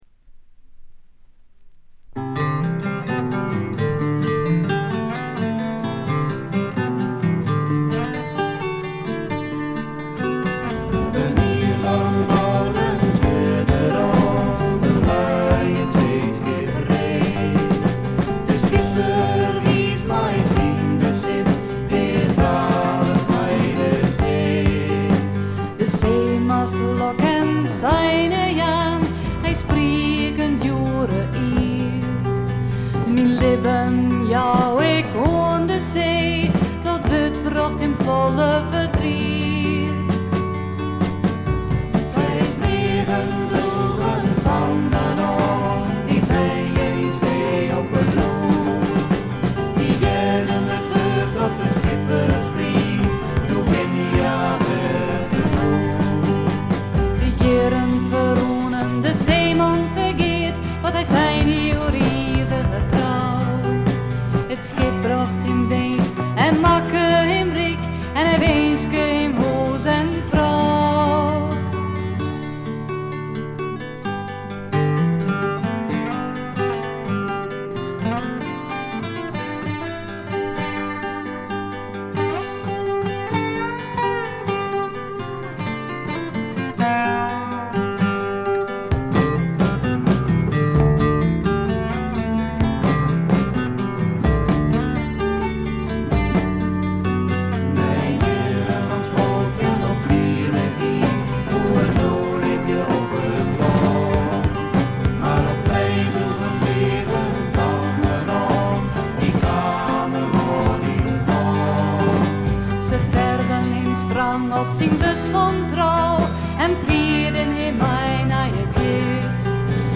部分試聴ですが、ほとんどノイズ感無し。
アコースティック楽器主体ながら多くの曲でドラムスも入り素晴らしいプログレッシヴ・フォークを展開しています。
試聴曲は現品からの取り込み音源です。
Accordion